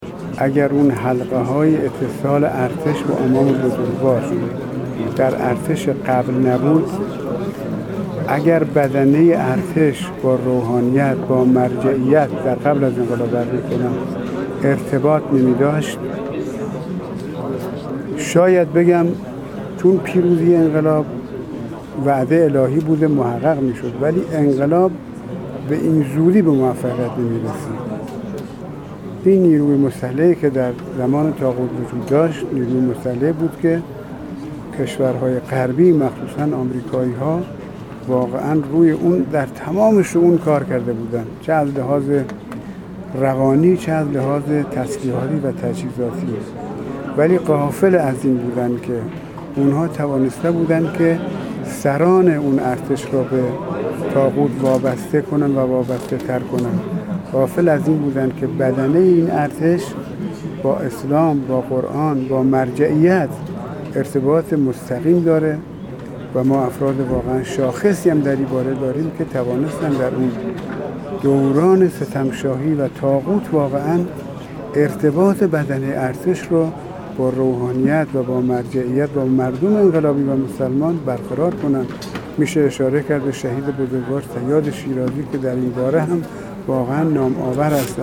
امیر سرتیپ کیومرث حیدری، فرمانده نیروی زمینی ارتشامیر سرتیپ کیومرث حیدری، فرمانده نیروی زمینی ارتش ج.ا.ا در گفت‌وگو با ایکنا ضمن تبریک چهل و چهارمین طلیعه پیروزی انقلاب اسلامی با اشاره به نقش ارتش در پیروزی انقلاب اسلامی اظهار کرد: اگر حلقه‌های اتصال ارتش به امام بزرگوار در ارتش پیش از پیروزی انقلاب اسلامی نبود و بدنه ارتش با روحانیت و مرجعیت اینگونه ارتباط نمی‌داشتند، شاید می‌توان گفت انقلاب به این زودی به موفقیت نمی‌رسید و به ثمر نمی‌نشست هر چند پیروزی انقلاب، وعده الهی بوده است.